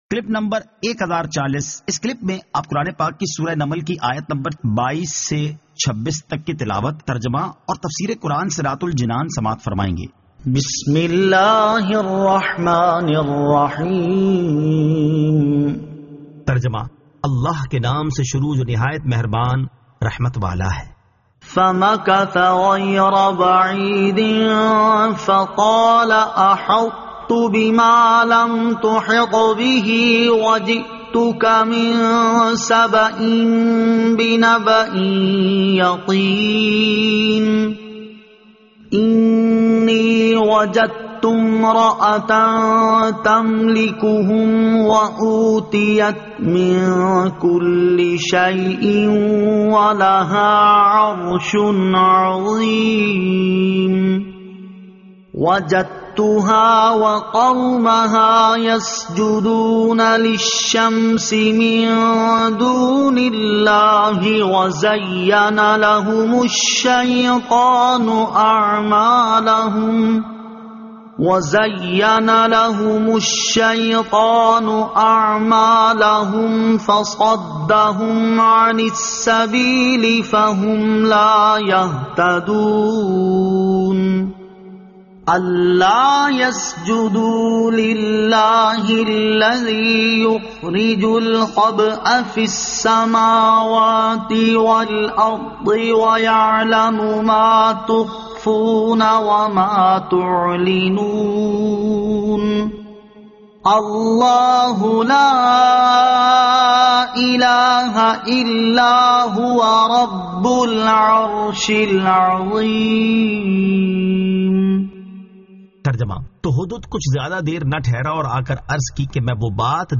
Surah An-Naml 22 To 26 Tilawat , Tarjama , Tafseer